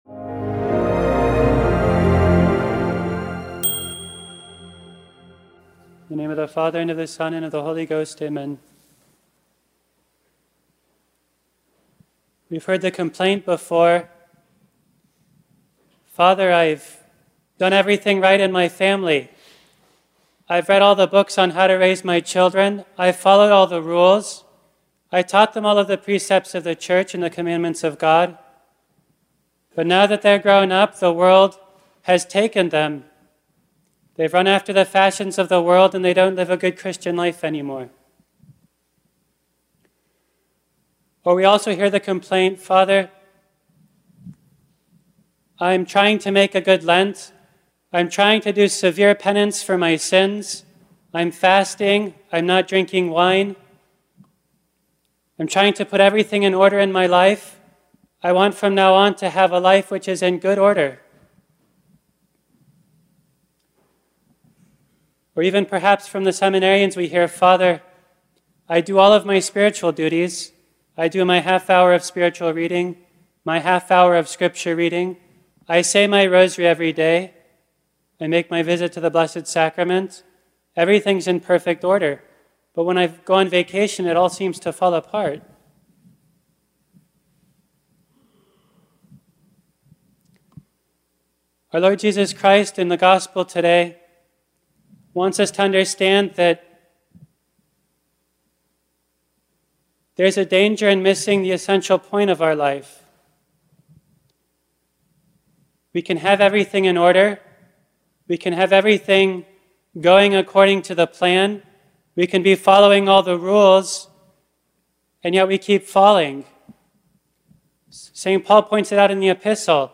Sermon-66-Audio-converted.mp3